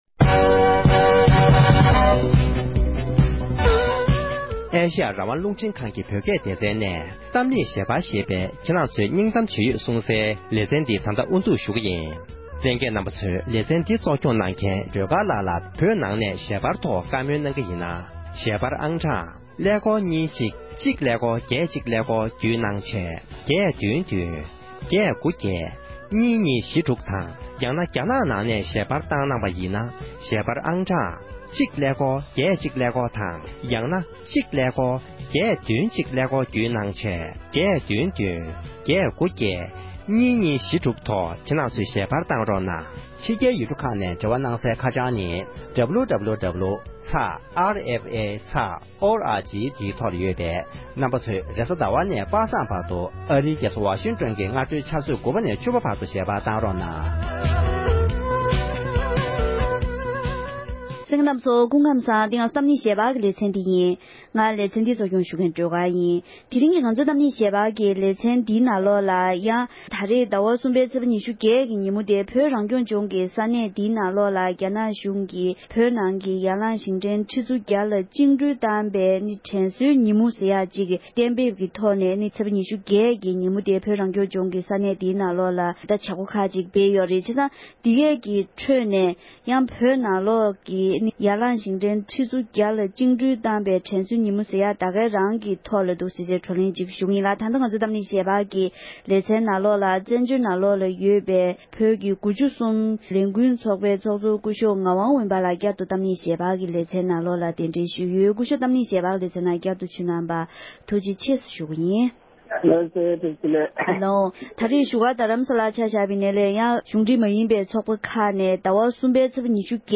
དེ་རིང་གི་གཏམ་གླེང་ཞལ་པར་གྱི་ལེ་ཚན